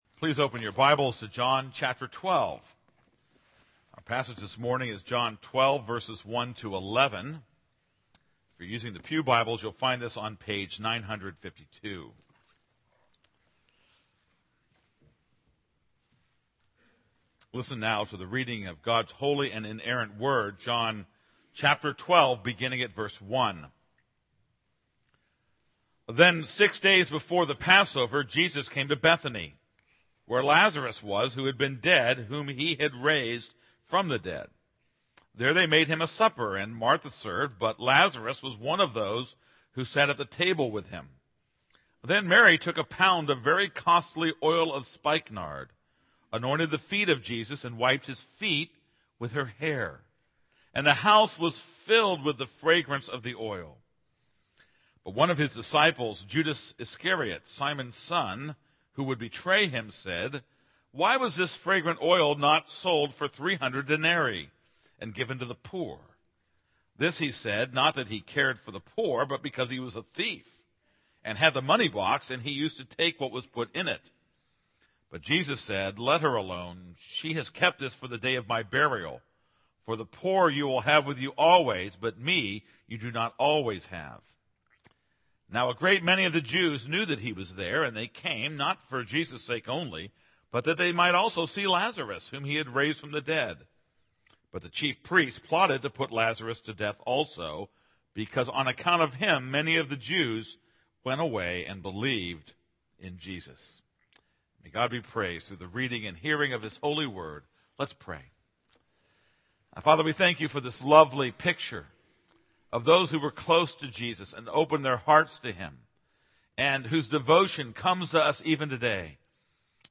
This is a sermon on John 12:1-11.